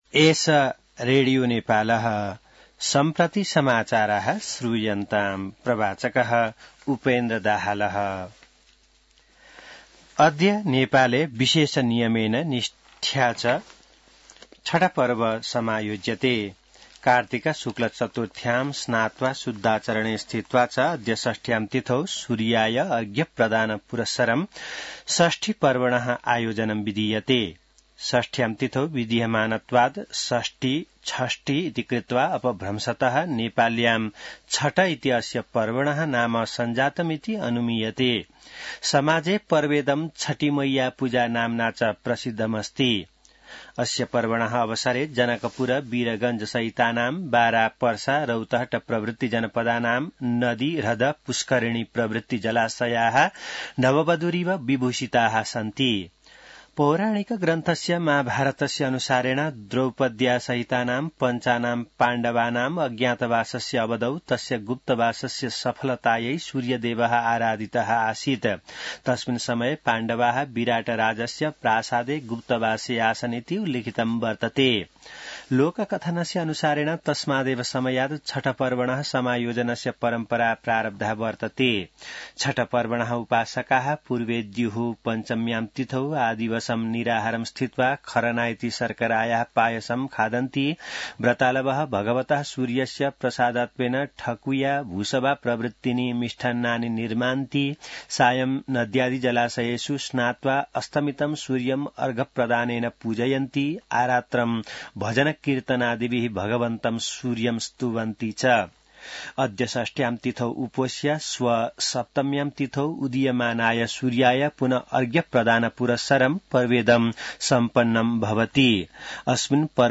संस्कृत समाचार : २३ कार्तिक , २०८१